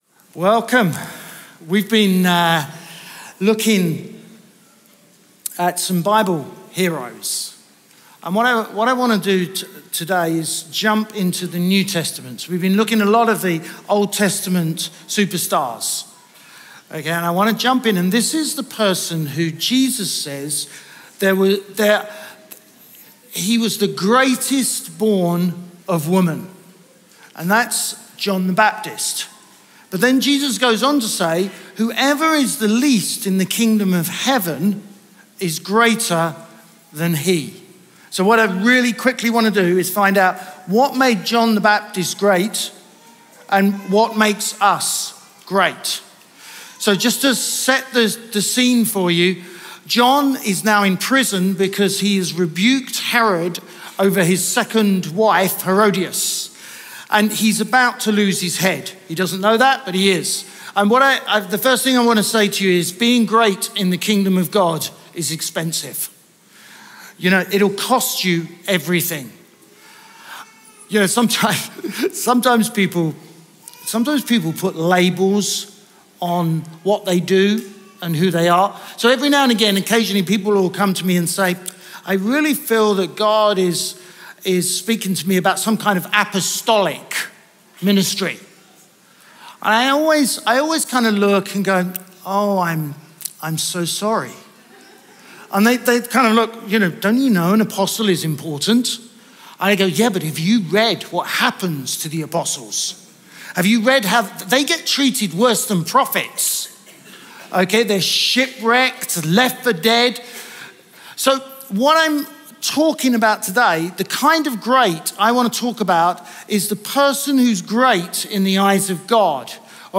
Chroma Church - Sunday Sermon John The Baptist Jul 04 2023 | 00:27:23 1x 00:00 / 00:27:23 Subscribe Share RSS Feed Share Link Embed